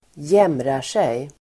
Uttal: [²j'em:rar_sej]